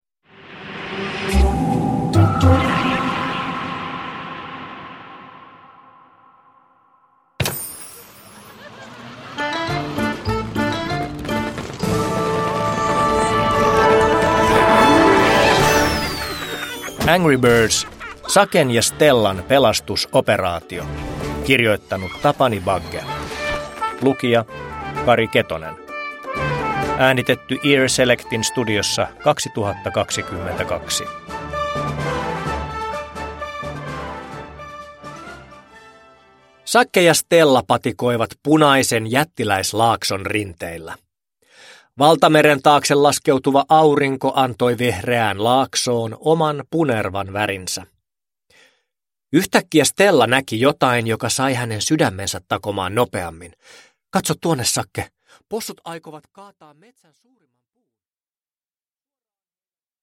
Angry Birds: Saken ja Stellan pelastuspartio – Ljudbok – Laddas ner